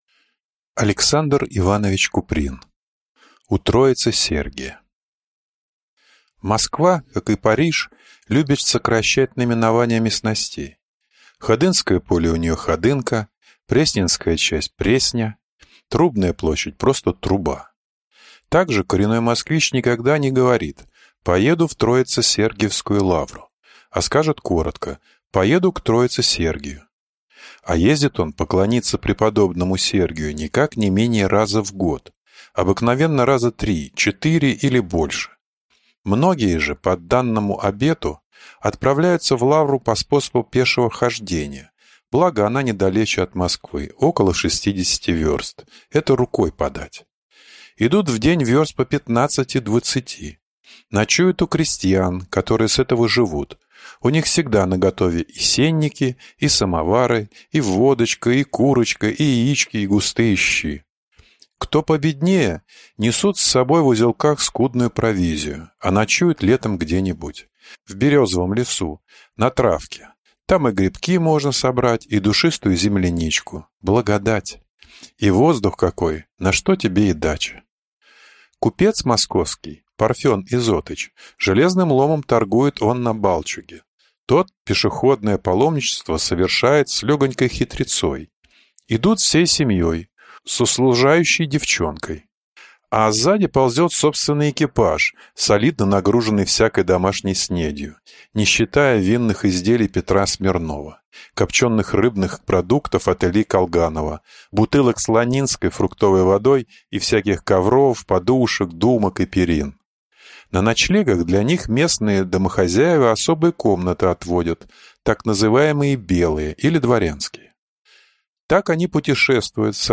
Аудиокнига У Троице-Сергия | Библиотека аудиокниг